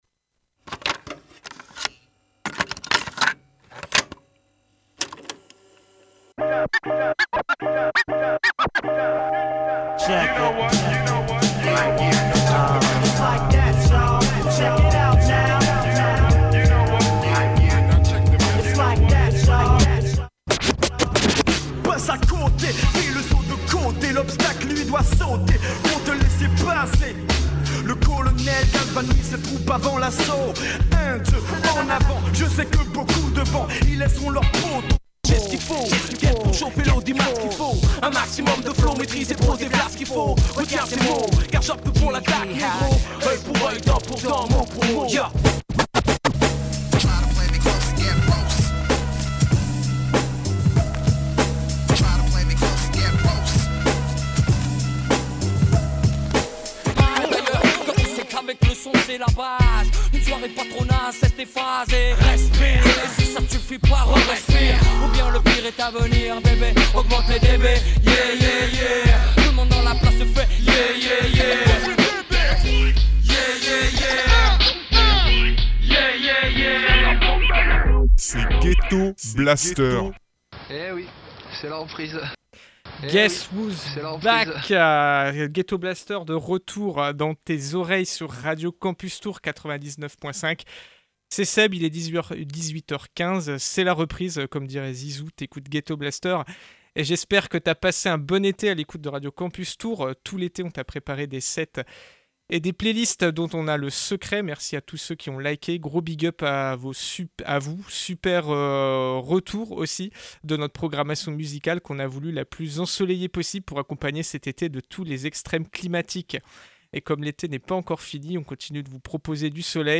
Pour se mettre en jambes, la playlist de l’émission de ce soir donnait le « la » soukoussé et cadencé de ce à quoi t’attendre: